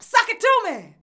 SUCK IT.wav